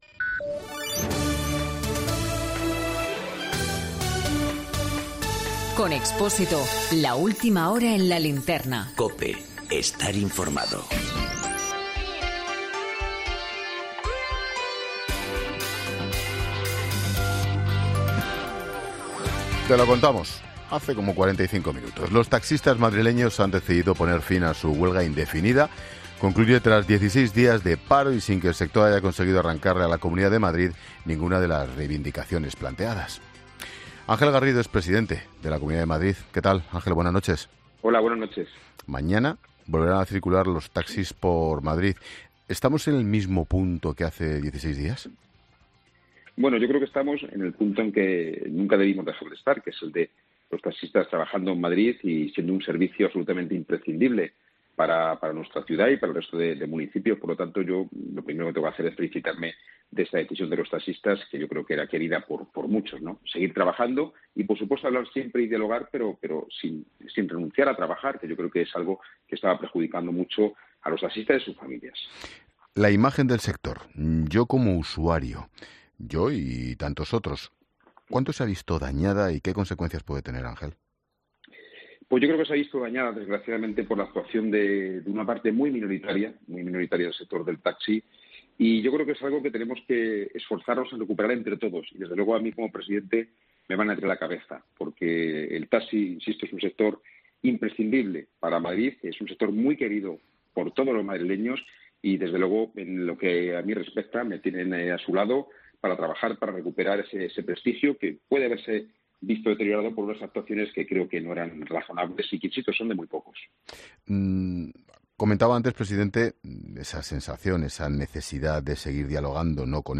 El presidente de la Comunidad de Madrid, Ángel Garrido, ha pasado por los micrófonos de La Linterna de COPE donde ha felicitado al colectivo del taxi por abandonar la huelga y marca la pauta de negociación de ahora en adelante en “sentarse y dialogar, pero sin dejar de trabajar”.